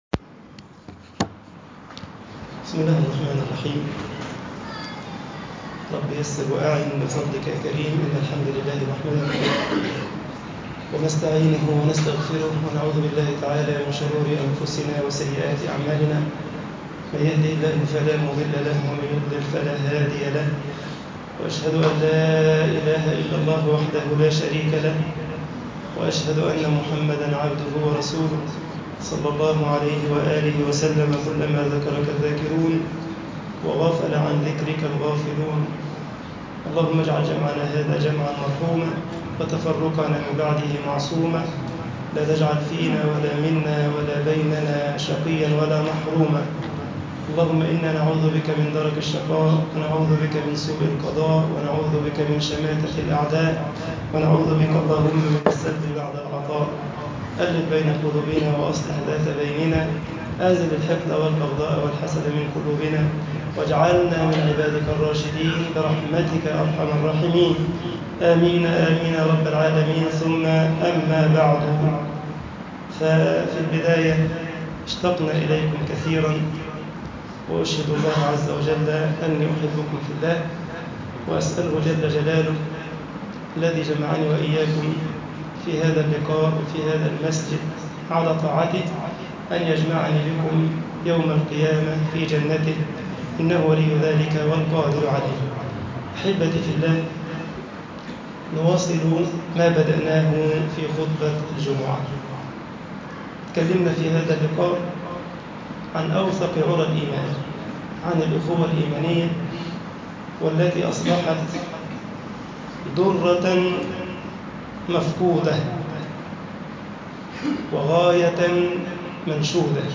إنما المؤمنون إخوة - محاضرة
Inama almuminouna ikhouah - muhadharah.mp3